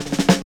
44 SN BUZZ-R.wav